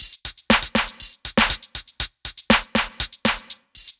Index of /musicradar/french-house-chillout-samples/120bpm/Beats
FHC_BeatA_120-01_HatClap.wav